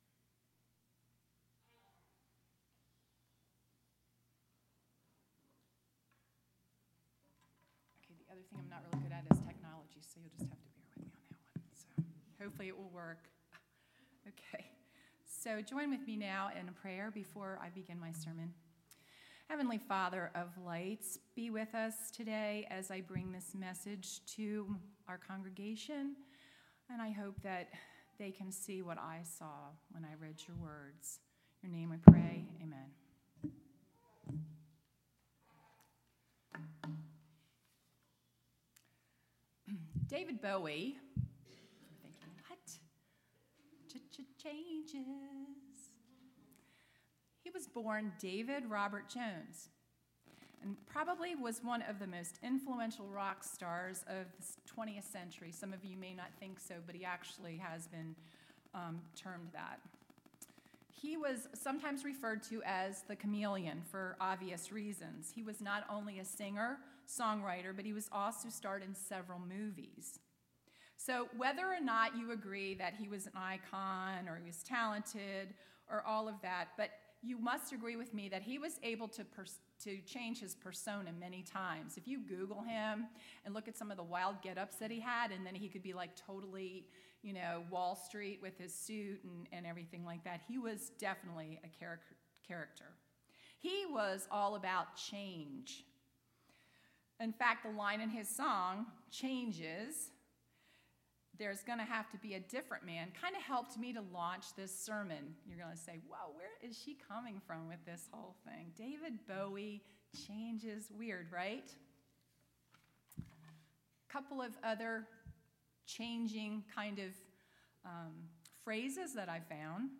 Sermon – Page 83